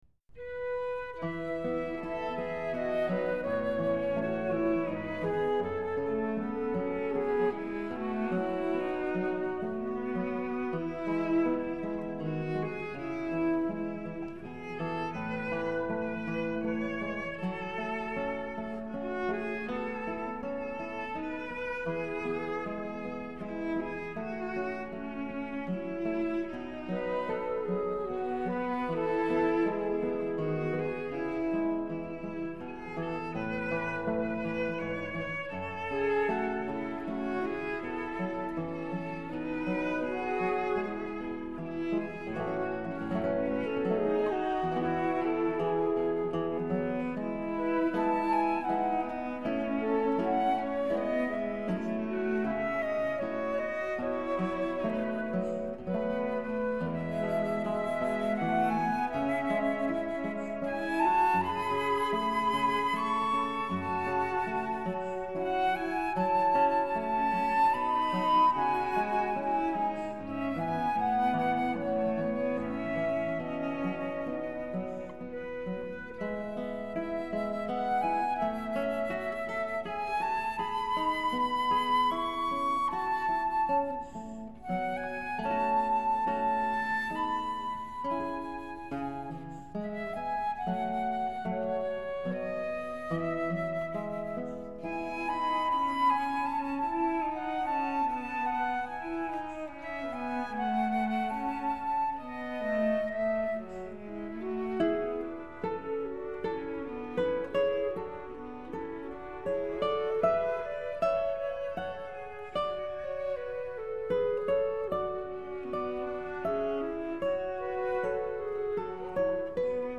SCORING:  Flute, viola, guitar